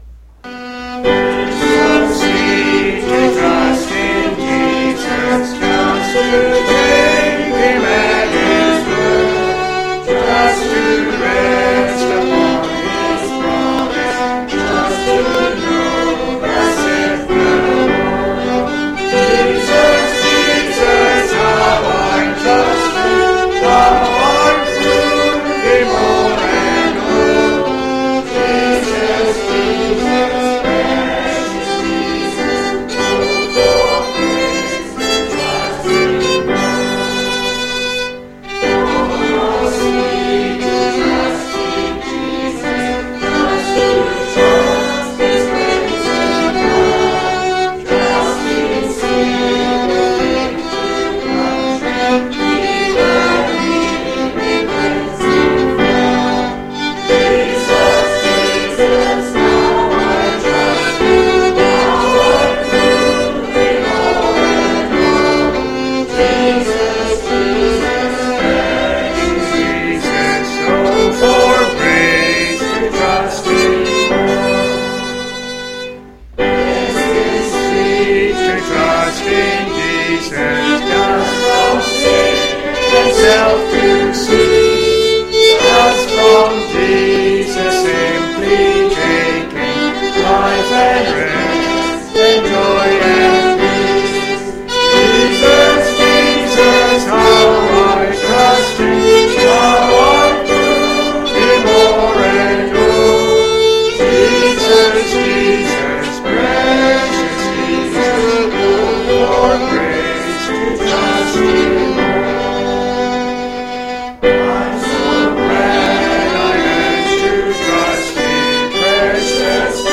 Church Congregation